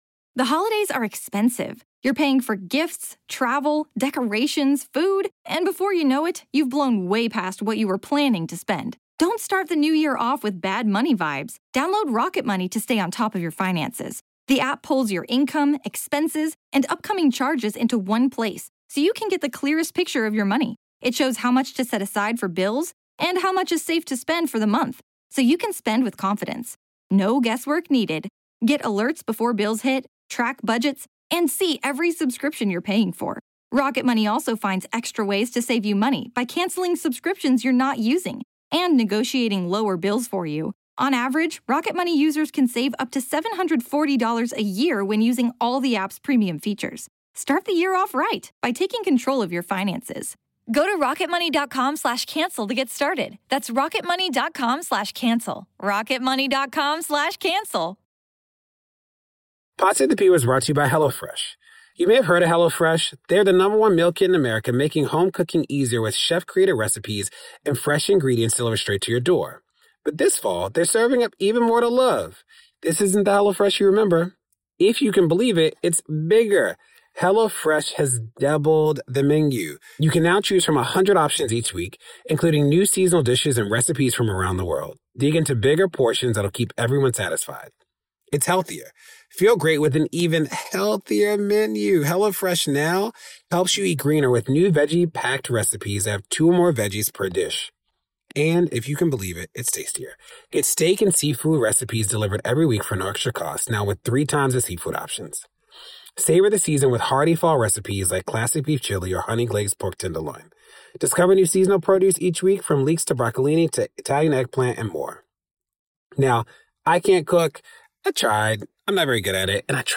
The U.S. threatens new sanctions on the International Criminal Court—so long as it agrees not to prosecute Donald Trump—while American service members quietly worry they’ll be left holding the legal bag for overseas military strikes. Plus, a reminder of what accountability and care can look like, as the Redd Family Collection of Black Art anchors community, history, and cultural power at the Tubman African American Museum. DeRay interviews author and historian